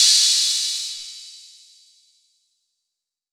6CRASH C.wav